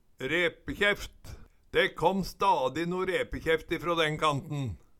Høyr på uttala Ordklasse: Substantiv hankjønn Kategori: Karakteristikk Attende til søk